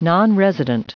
Prononciation du mot : nonresident